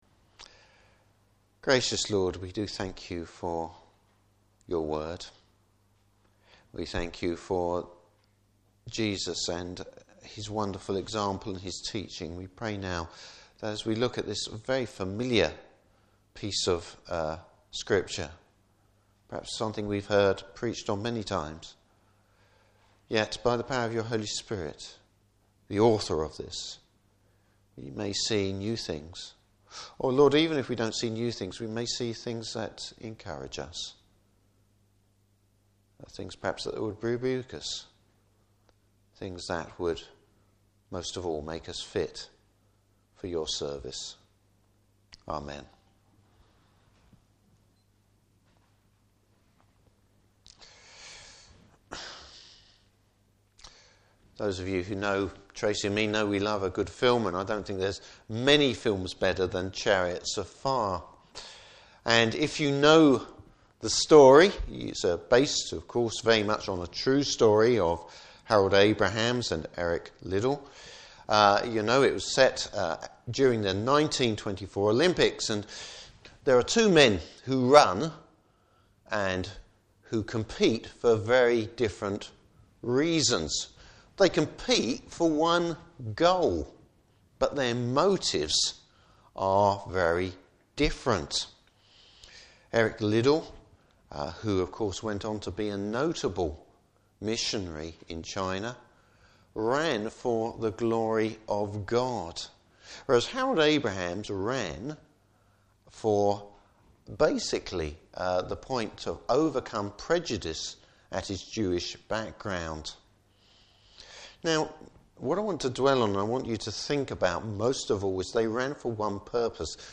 Service Type: Morning Service Bible Text: Luke 10:38-42.